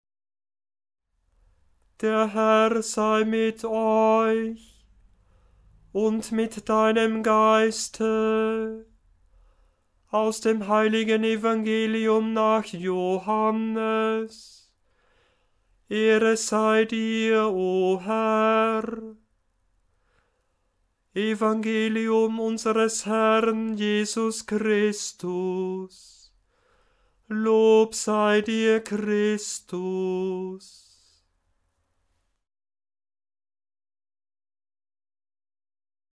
Die verschiedenen Einleitungen sind in der Tonhöhe absteigend geordnet,
Einleitung zum Evangelium nach Johannes 883 KB Erster Ton a, wie im Lektionar notiert
evg_johannes_dt_ton-a3.mp3